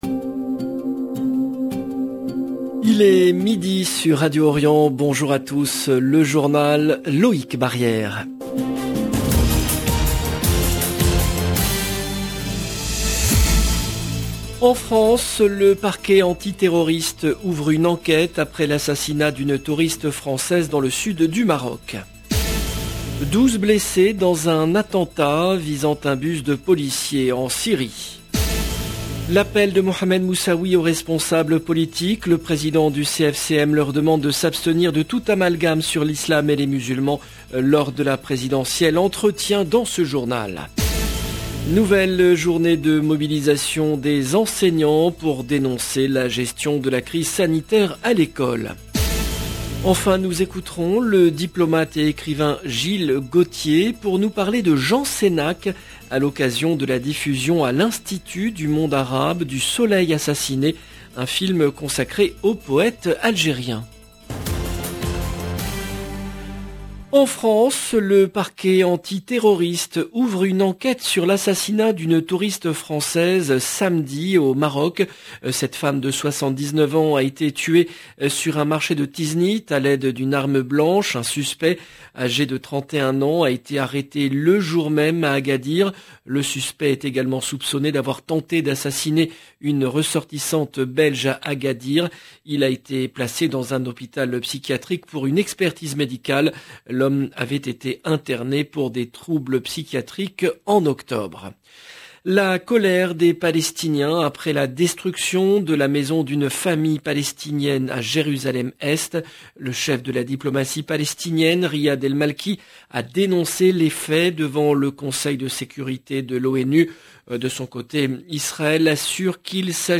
Entretien dans ce journal. Nouvelle journée de mobilisation des enseignants pour dénoncer la gestion de la crise sanitaire à l’école.